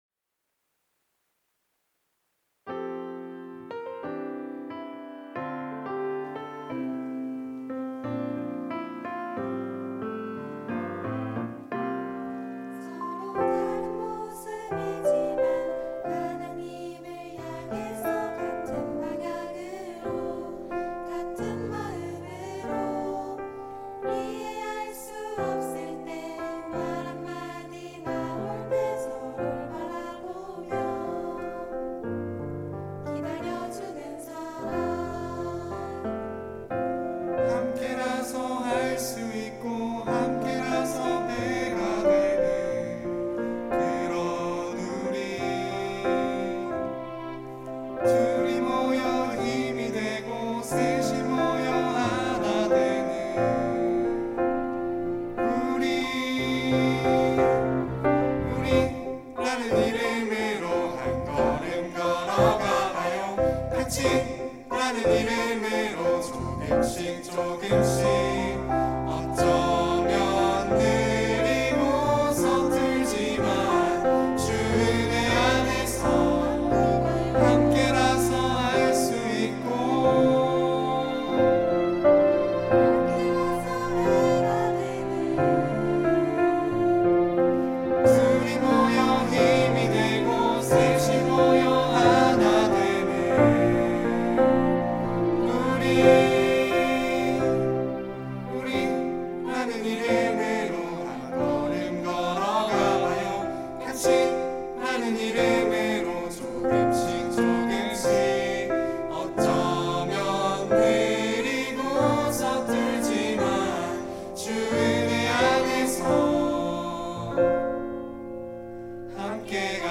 특송과 특주 - 우리, 같이
청년부 2022년도 4팀 리더십